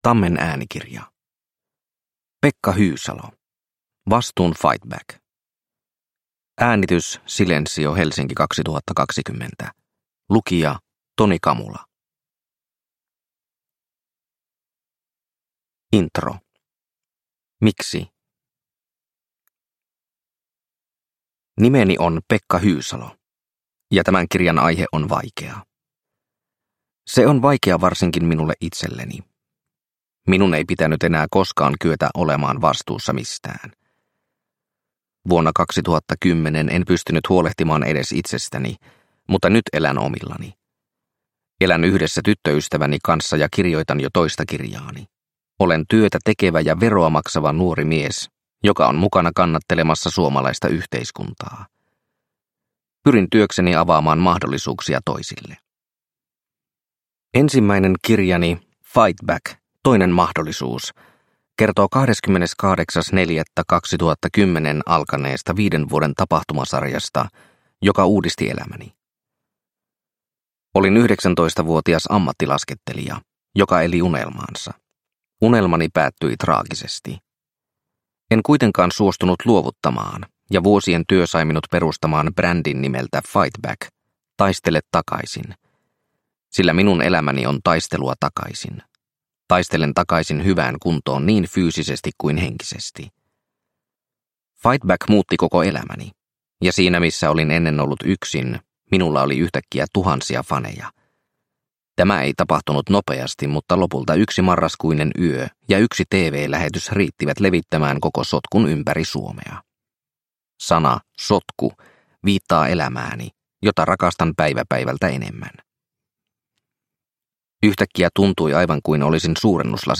Vastuun FightBack – Ljudbok – Laddas ner